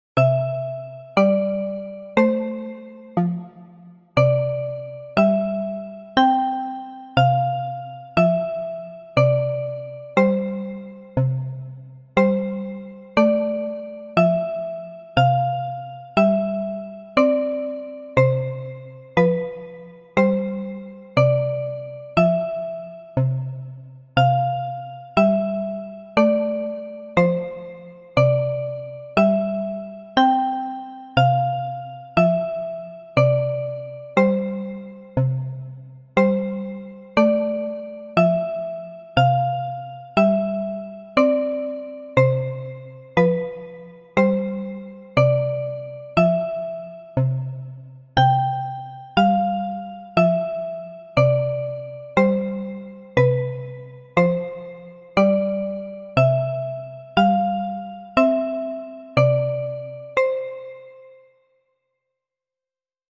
悲しげな曲です。
BPM60 オルゴール
ゆっくり